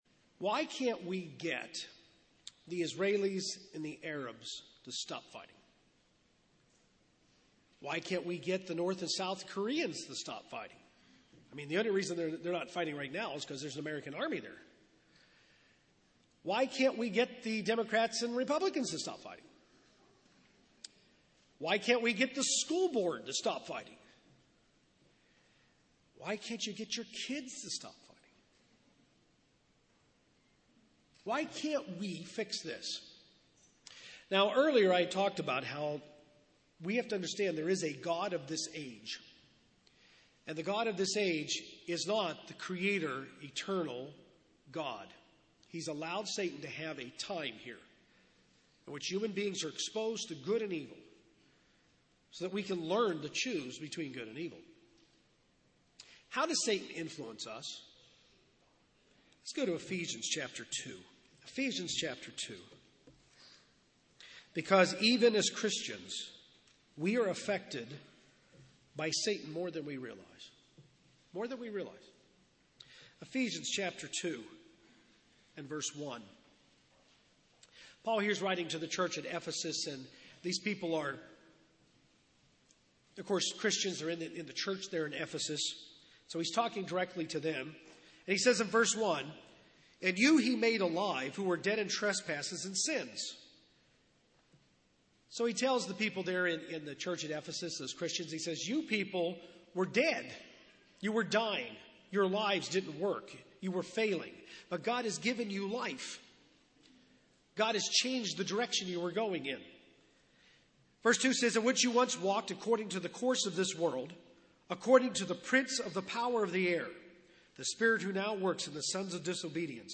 Learn why the world needs the Kingdom in this Kingdom of God seminar.